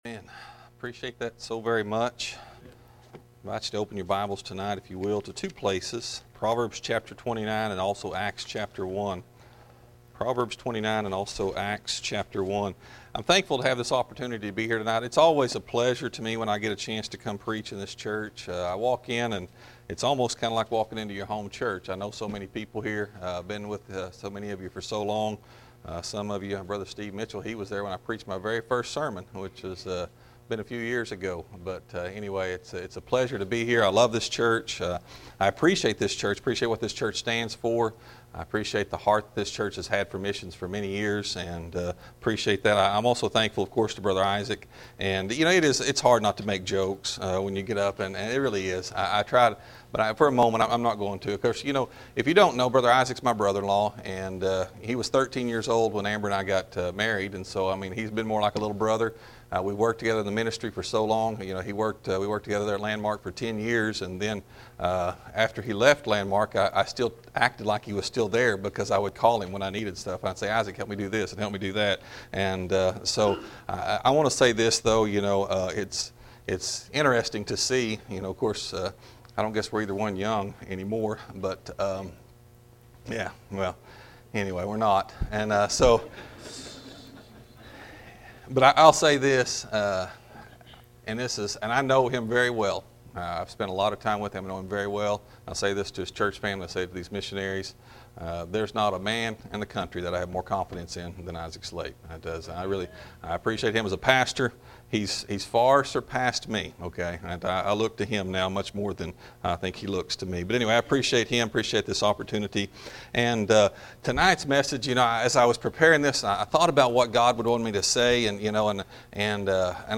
Missions Conference Day 1 Evening Services (Having A Vision For Missions)